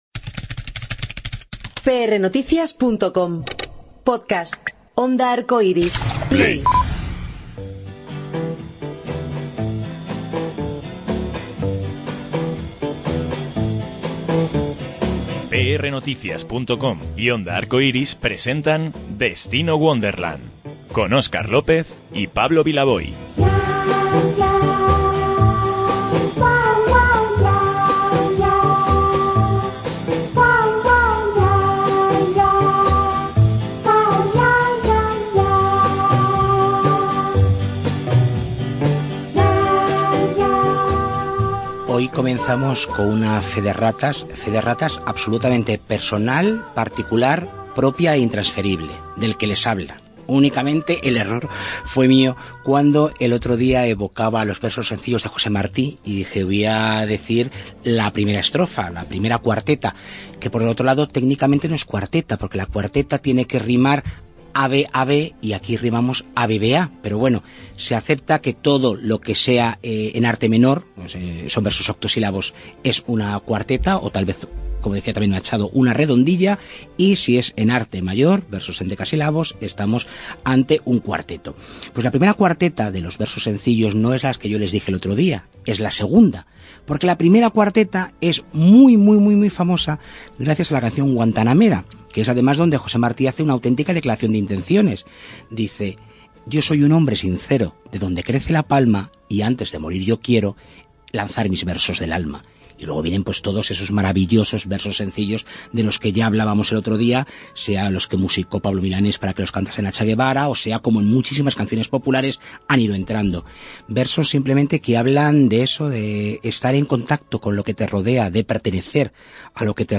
María Adánez atendió a Destino: Wonderland mientras se preparaba para la representación de El Pequeño Poni, de la que nos presenta los detalles.